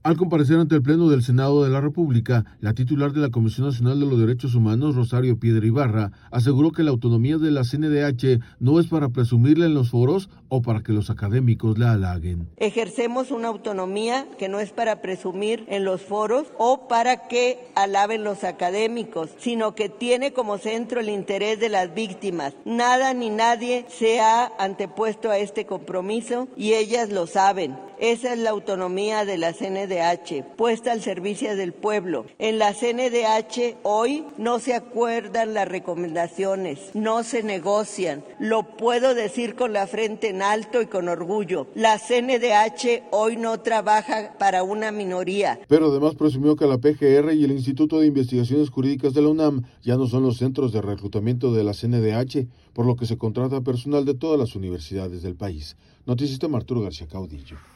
Titular de la CNDH defiende la autonomía de la defensoría ante los senadores
Al comparecer ante el pleno del Senado de la República, la titular de la Comisión Nacional de los Derechos Humanos, Rosario Piedra Ibarra, aseguró que la autonomía de la CNDH no es para presumirla en los foros o para que los académicos […]